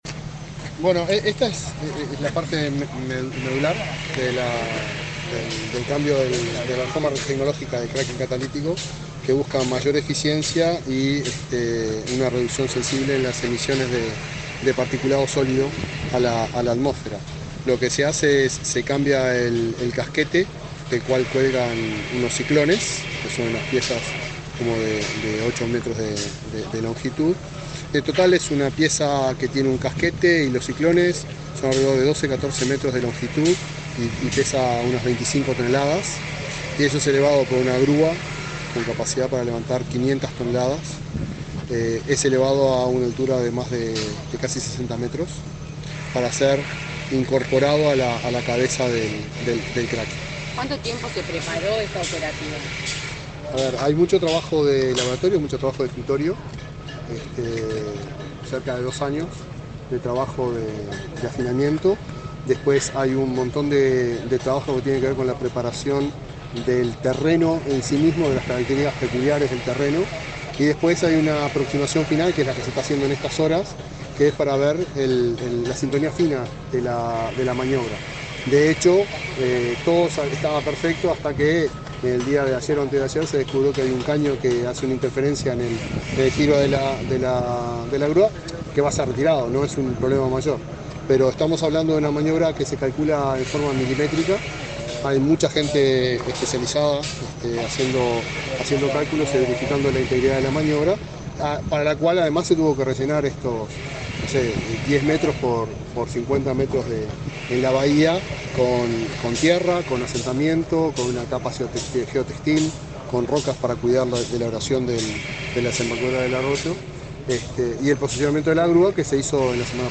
Declaraciones del presidente de Ancap a la prensa
Declaraciones del presidente de Ancap a la prensa 27/11/2023 Compartir Facebook X Copiar enlace WhatsApp LinkedIn El presidente de Ancap, Alejandro Stipanicic, dialogó con la prensa, en el marco de una recorrida que realizó, este lunes 17, por las obras en la refinería de La Teja.